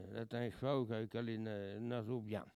locutions vernaculaires
Catégorie Locution